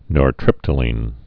(nôr-trĭptə-lēn)